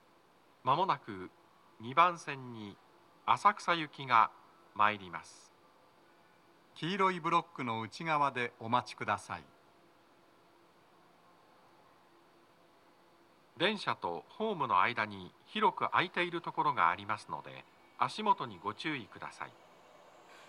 スピーカー種類 TOA天井型
🎵接近放送
鳴動は、やや遅めでした。
男声